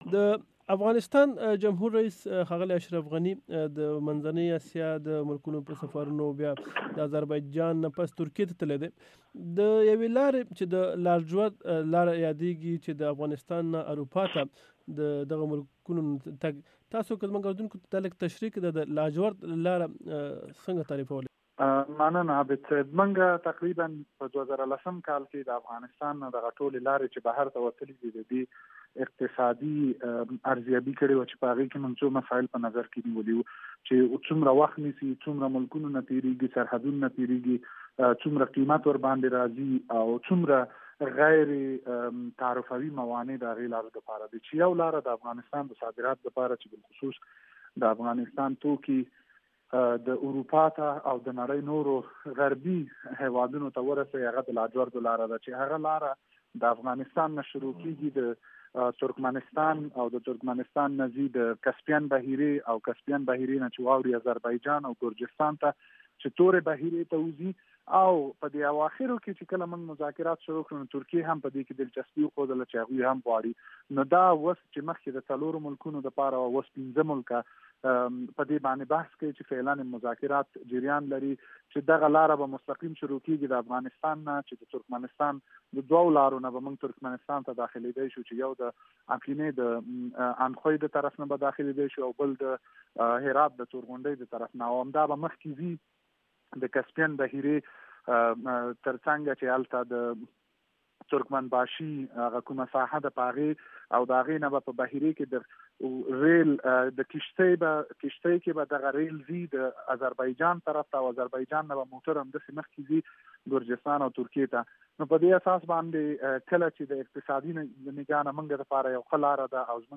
د افغانستان د تجارت وزارت مرستیال مزمل شینواري امریکا غږ اشنا سره مرکه کې وویل افغانستان چې سمندر ته لار نه لري اوس د خپلو صادراتو او وارداتو لپاره د پاکستان او ايران د بندرونه نه کار اخلي.